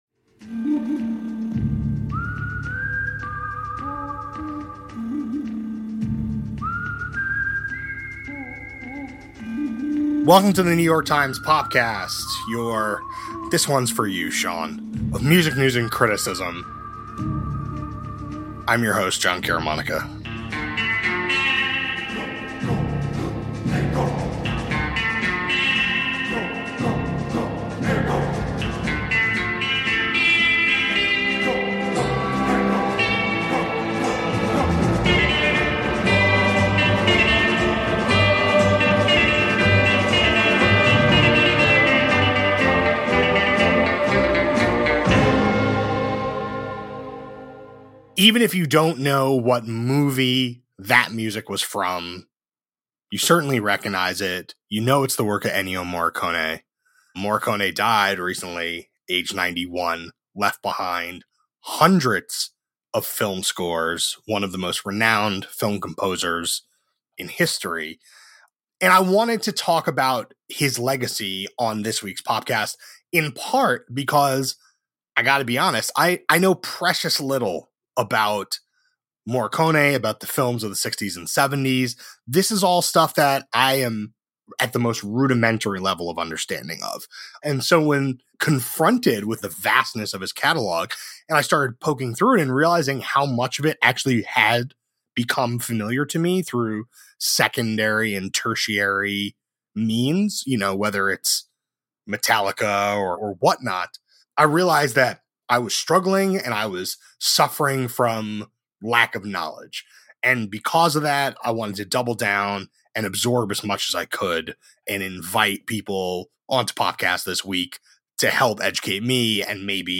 A conversation about how Ennio Morricone’s music shaped the films he worked on.